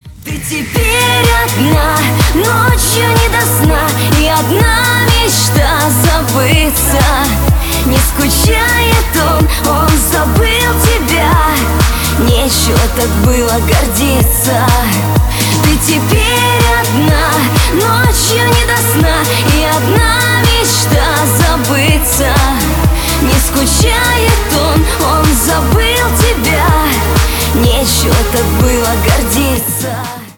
грустные , душевные
шансон